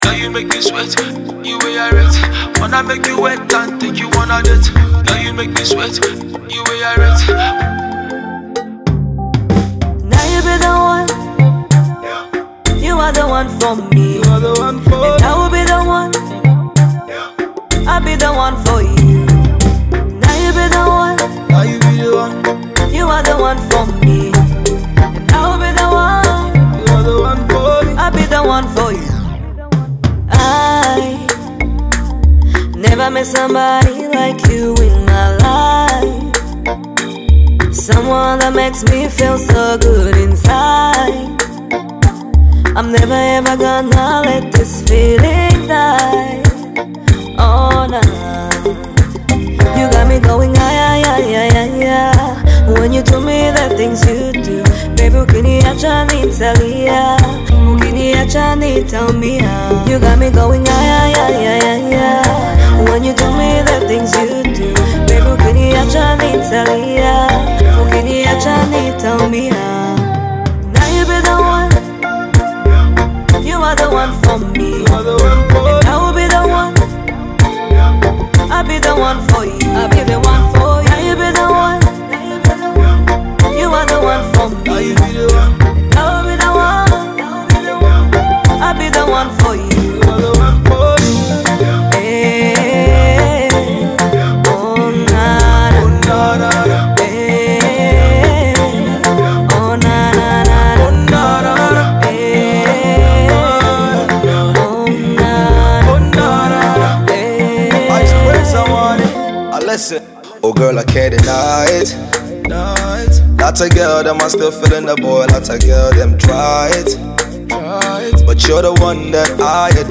Afrobeat sensation